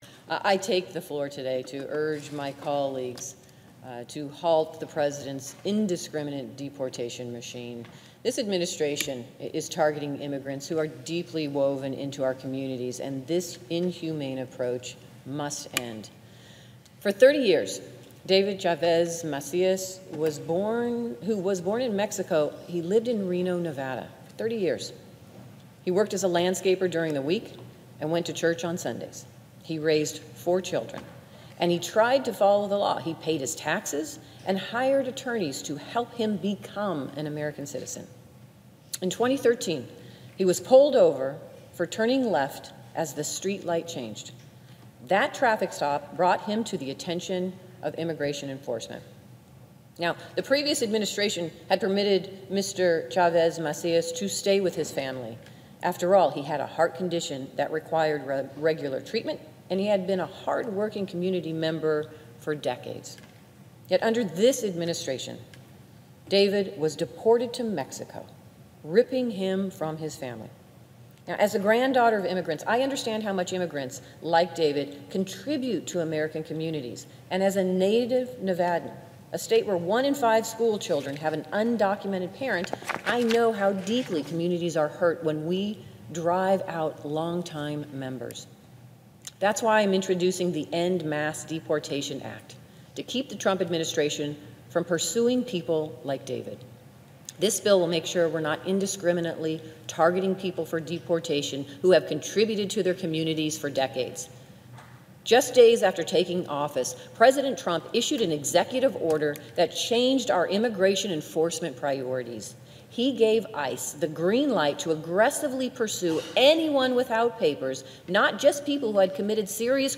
Cortez Masto Floor Speech
Washington, D.C. – U.S. Senator Catherine Cortez Masto (D-Nev.) delivered remarks on the Senate floor urging her colleagues to support the End Mass Deportation Act.
end-mass-deportation-act-floor-speech-